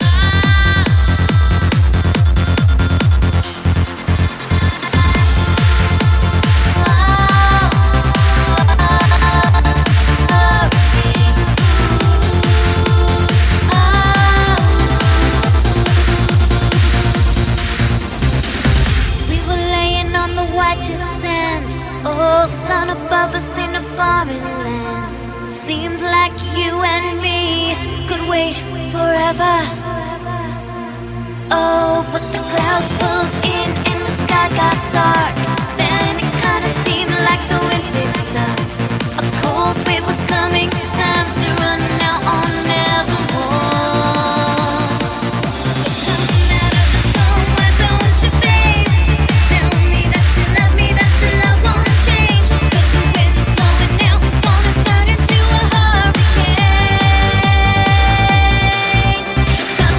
Электронная
Сборник европейских танцевальных хитов.